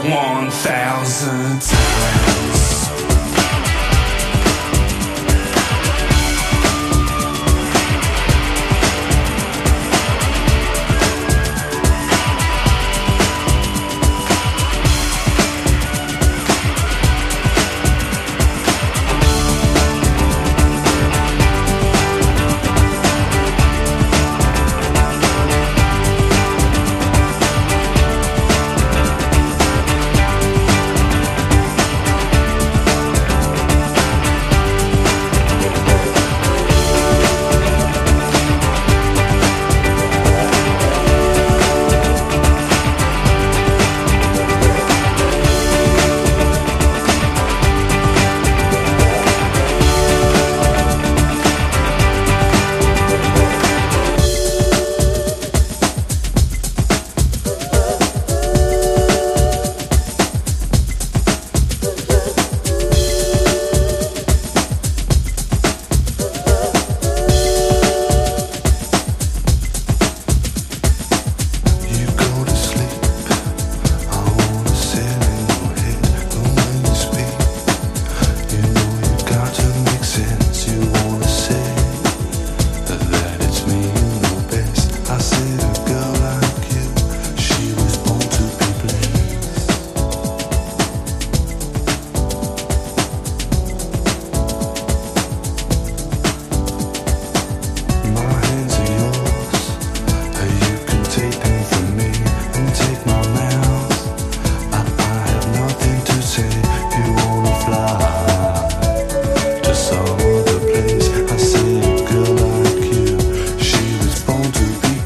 マッドチェスター/インディーダンス好きも必聴！